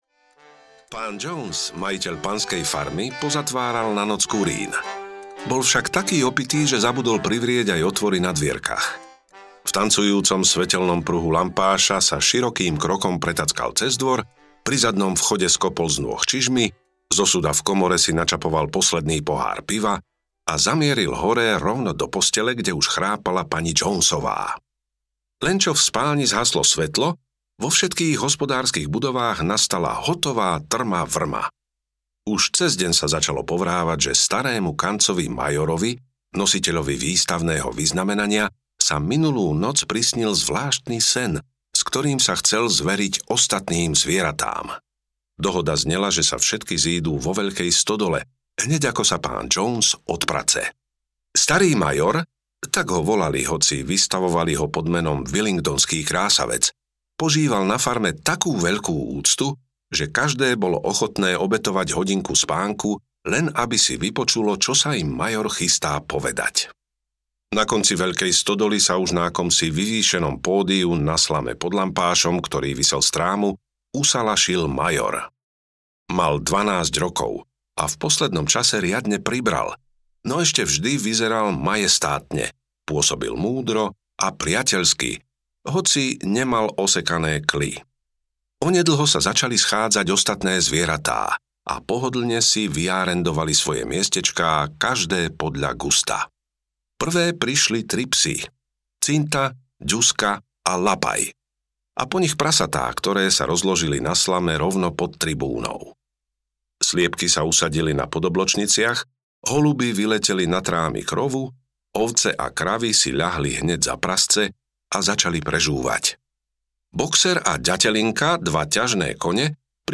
Zvieracia farma audiokniha
Ukázka z knihy